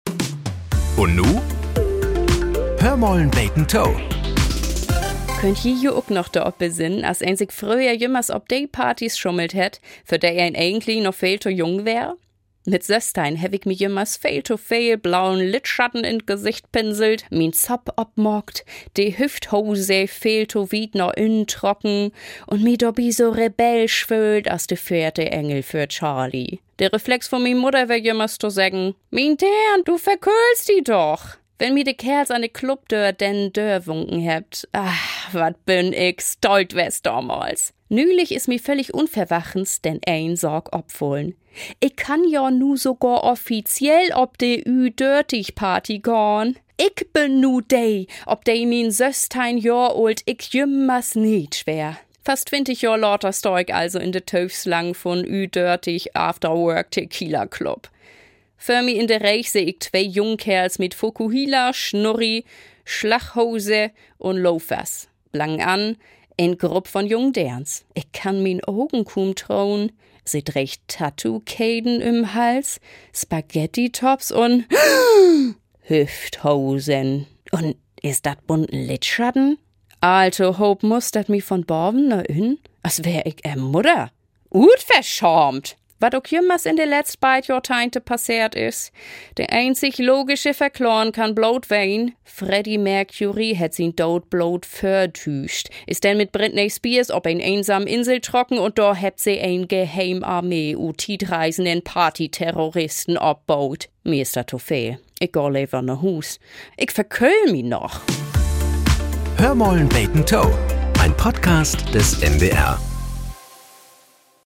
Nachrichten - 18.02.2025